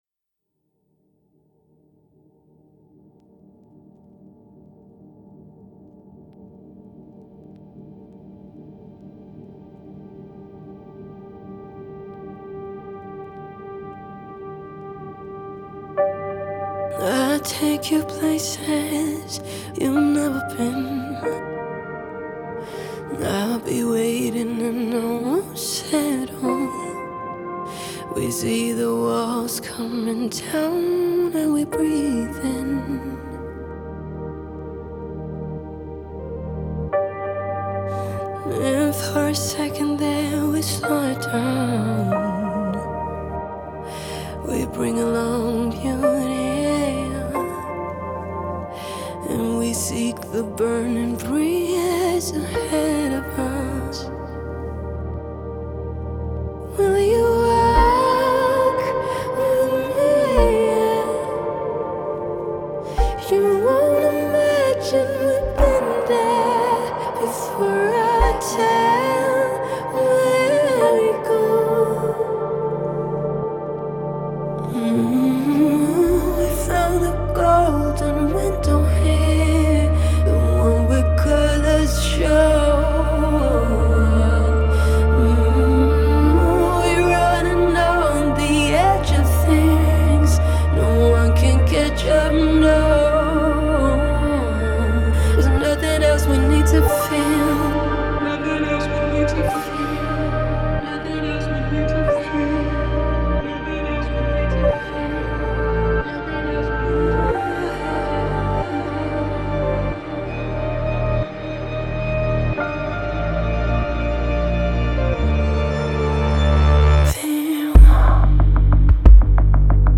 мощная поп-баллада шведской певицы
выразительный вокал и динамичное звучание